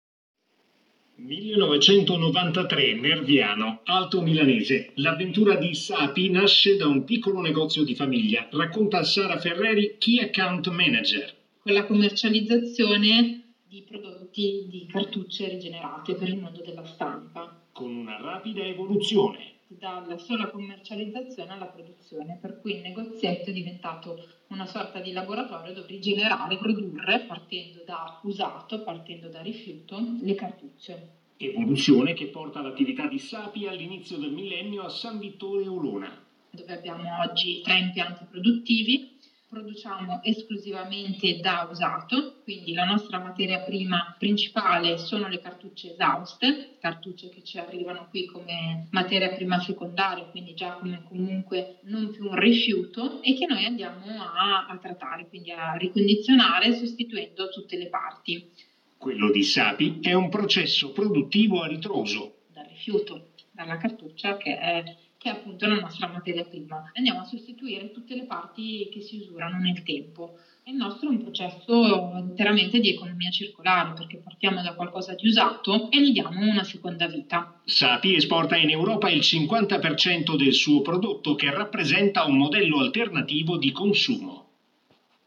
Puoi Ascoltare l’intervista direttamente sul canale RDS dedicato o cliccando il tasto Play qui sotto.